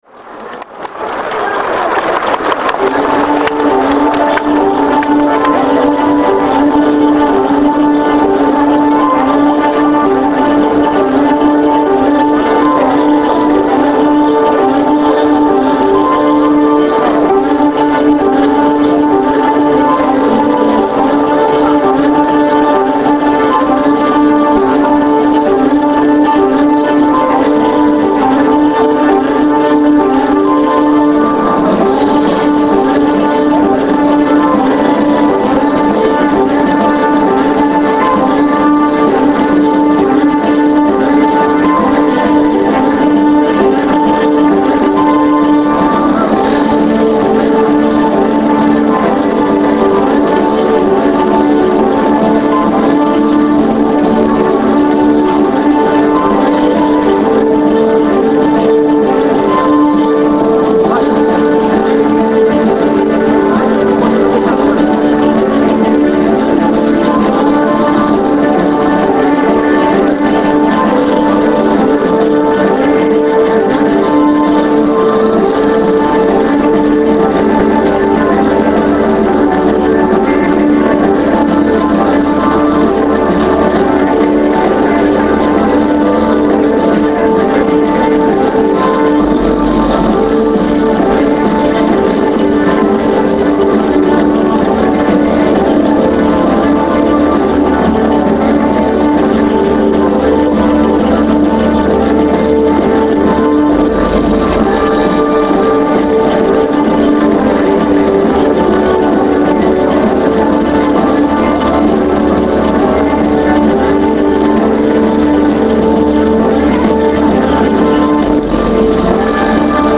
the curtain drops and the crowd goes wild!